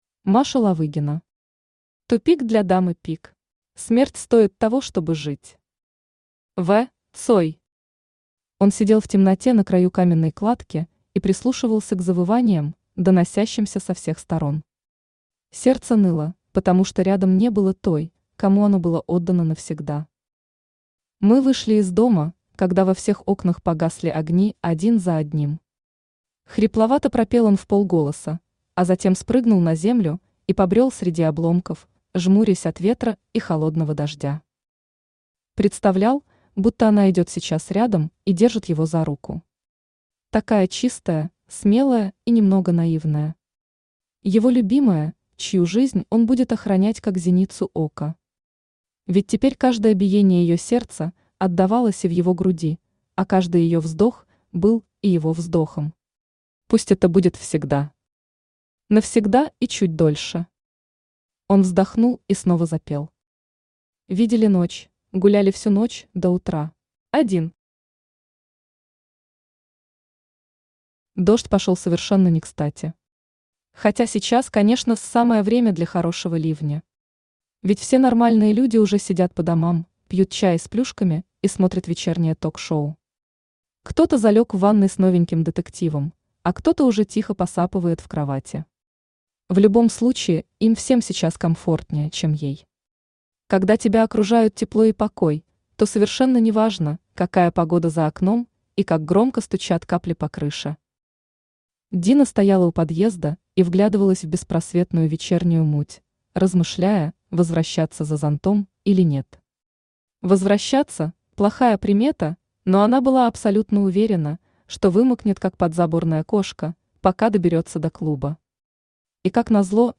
Аудиокнига Тупик для Дамы Пик | Библиотека аудиокниг
Aудиокнига Тупик для Дамы Пик Автор Маша Ловыгина Читает аудиокнигу Авточтец ЛитРес.